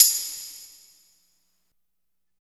62 TAMB   -R.wav